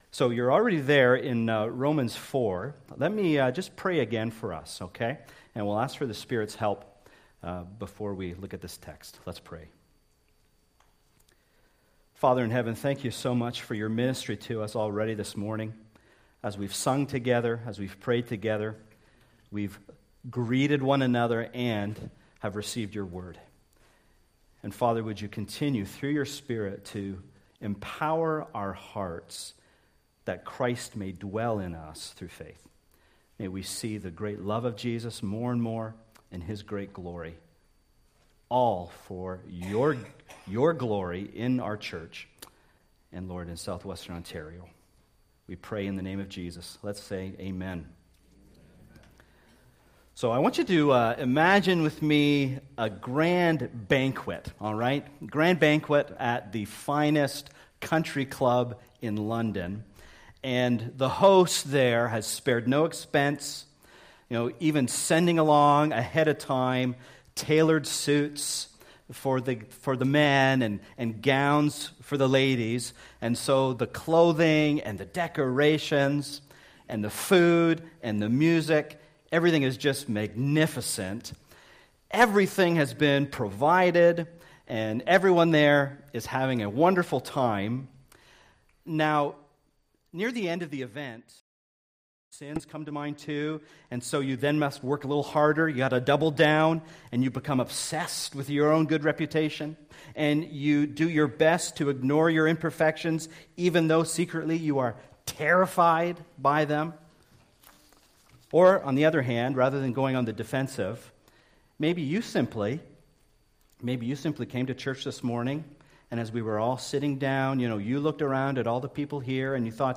Please note, there are a few minutes missing due to an issue with the recording.
Sermons